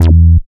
70.07 BASS.wav